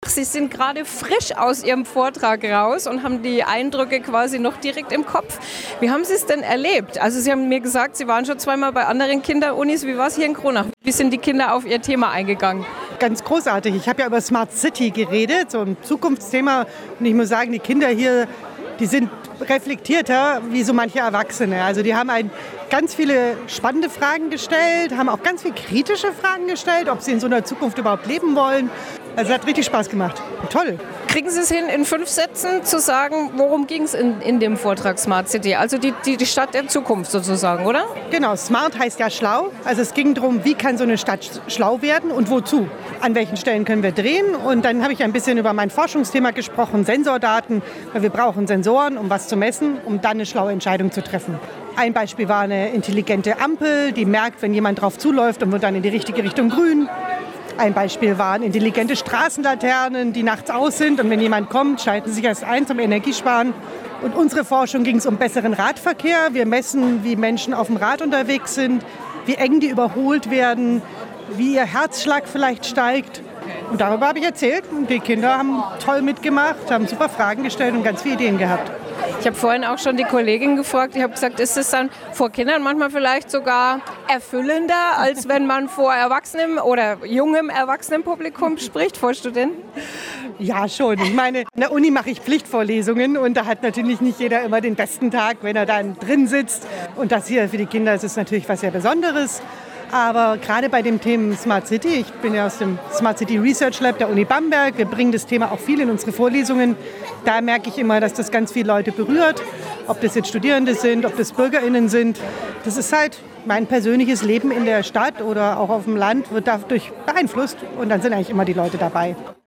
Radio Eins hat sowohl eine Dozentin als auch Nachwuchsstudentinnen und -studenten interviewt: